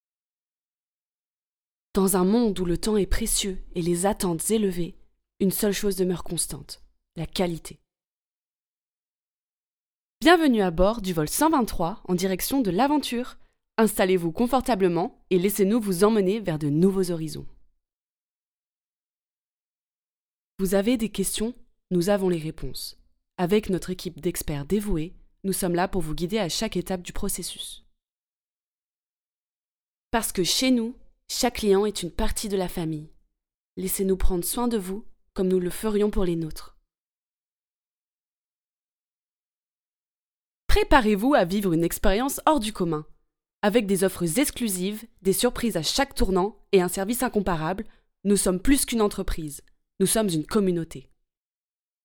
DEMO VOIX OFF